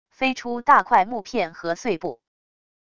飞出大块木片和碎布wav音频